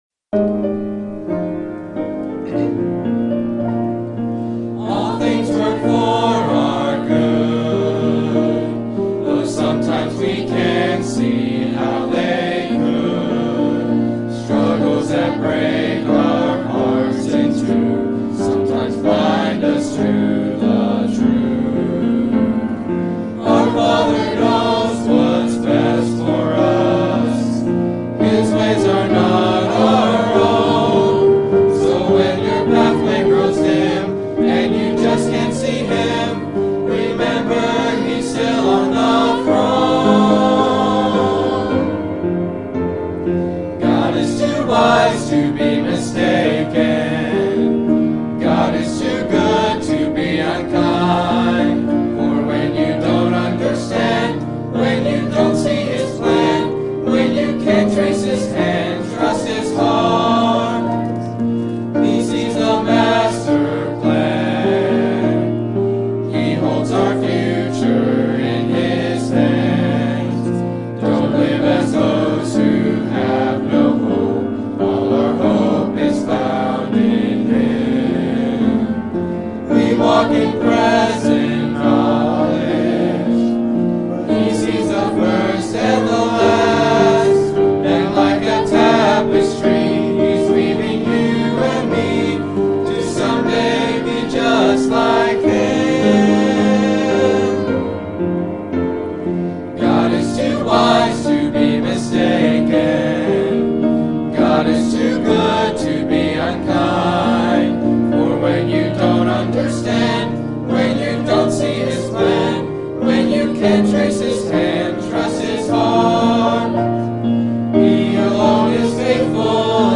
Sermon Topic: General Sermon Type: Service Sermon Audio: Sermon download: Download (30.5 MB) Sermon Tags: Psalm Riches Debt Wealth